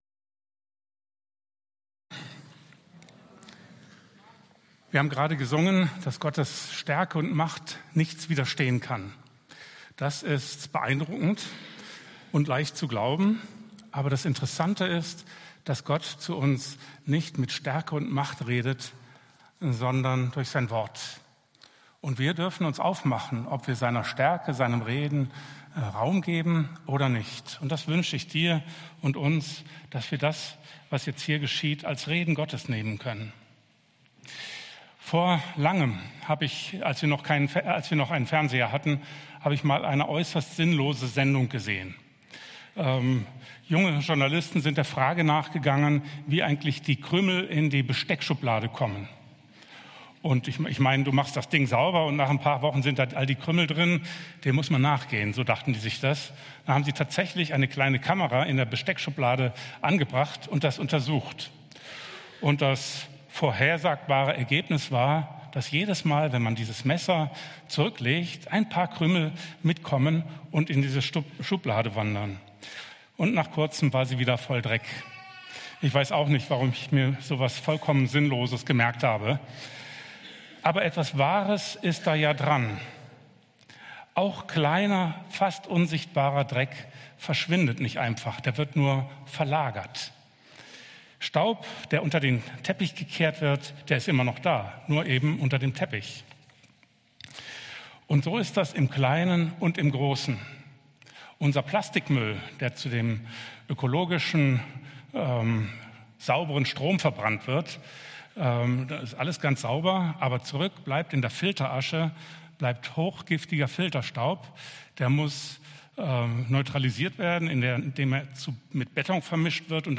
Gottes unerhörte Gnade für eine verlorene Welt ~ EFG-Haiger Predigt-Podcast Podcast